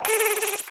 buzz.mp3